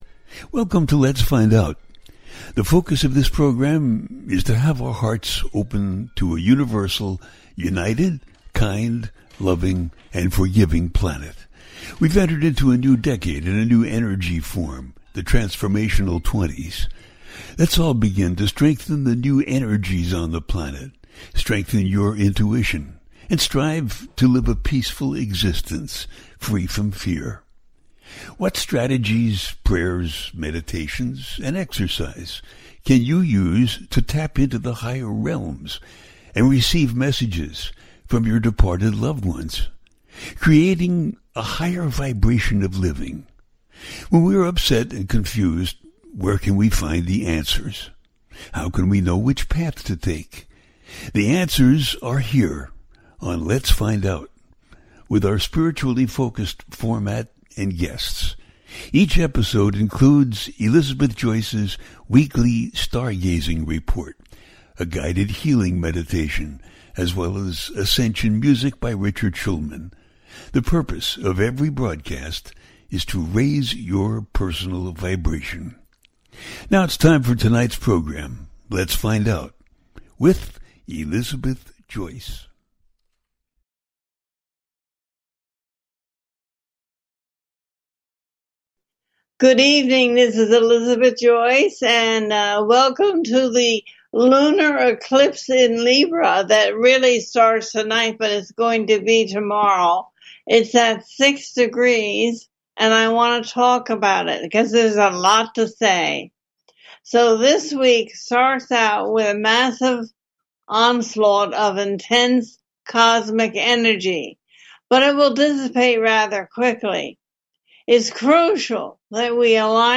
Talk Show Episode, Audio Podcast, Lets Find Out and It is crucial that we align our energies with the unfolding cosmic rhythm coming out of the center of our galaxy.